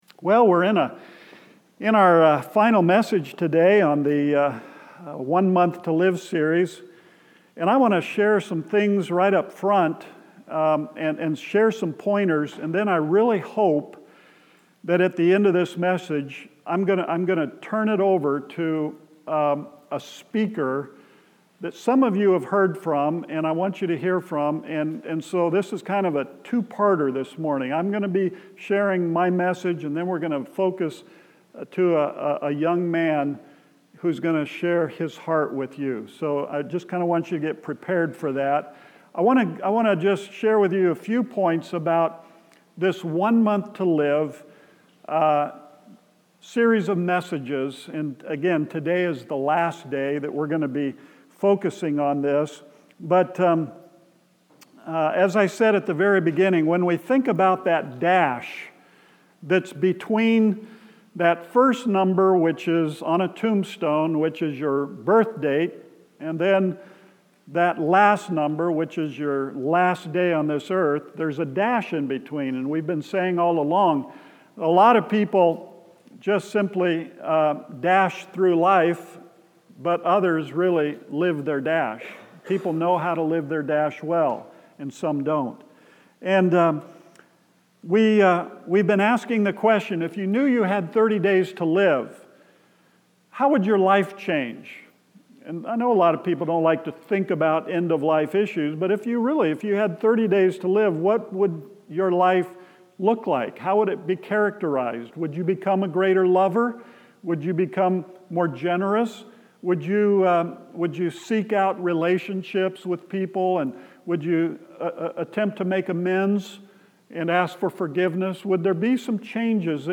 Central Baptist Church Sermons